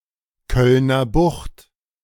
The Cologne Lowland,[1][2] also called the Cologne Bay[1] or, less commonly, the Cologne Bight (German: Kölner Bucht, pronounced [ˈkœlnɐ ˈbʊxt]